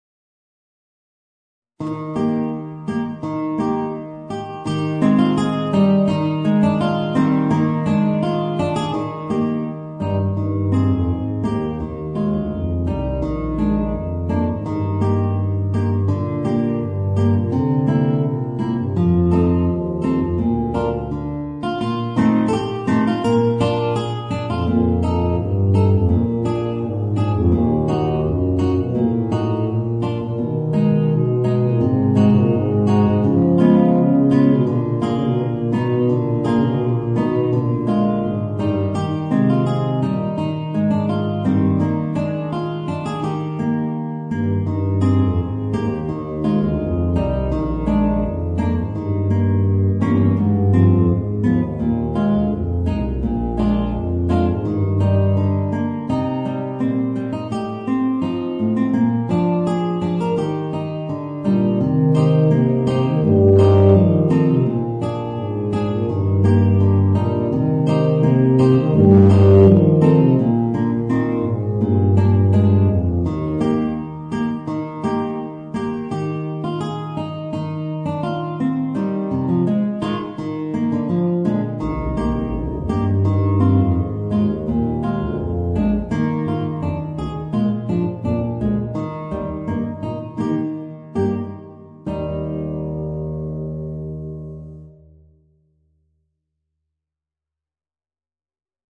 Voicing: Guitar and Bb Bass